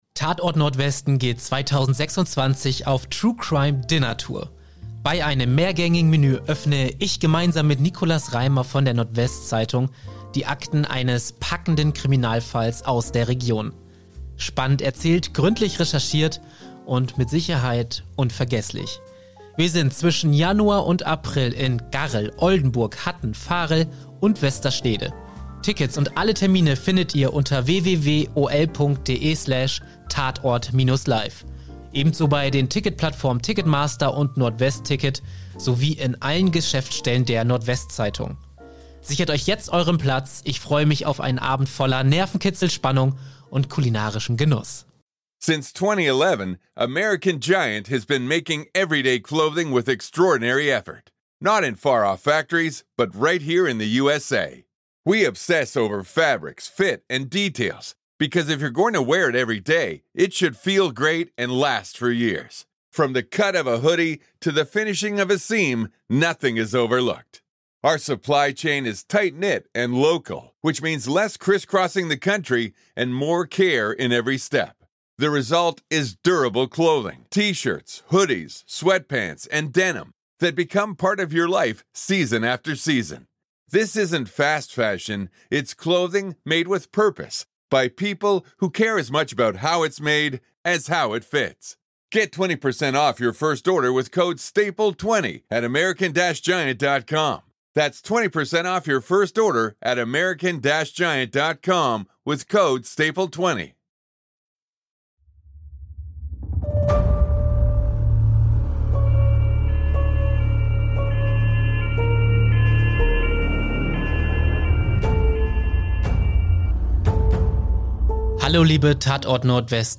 Ihr kört einen exklusiven Mitschnitt der Live-Aufnahme in Wilhelmshaven.